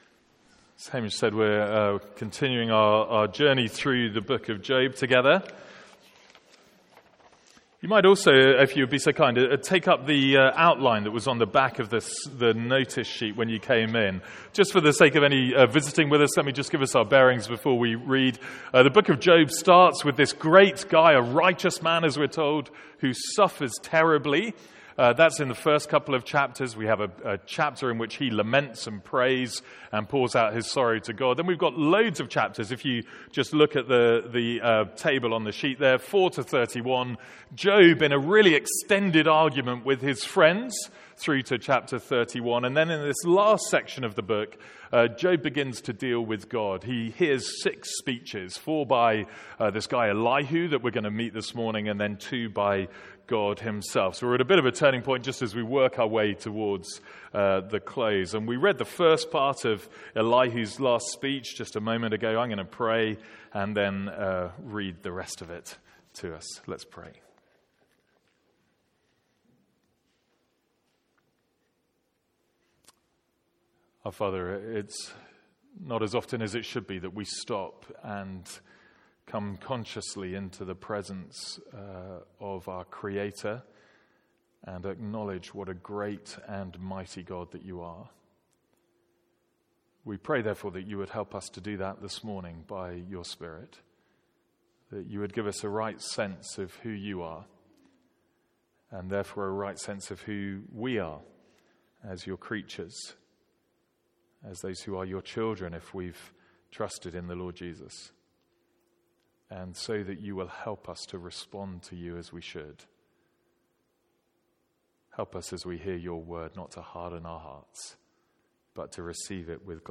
Sermons | St Andrews Free Church
From our morning series in Job.